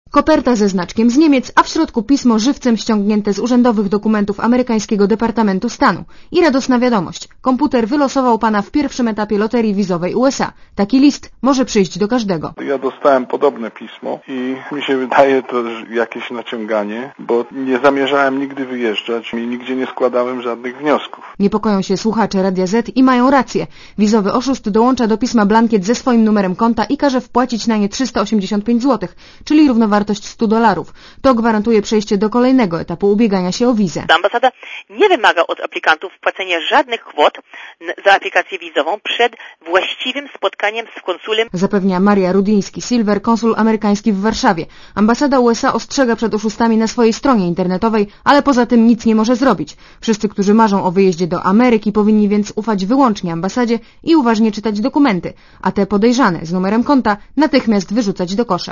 Posłuchaj relacji reporterki Radia ZET (232 KB)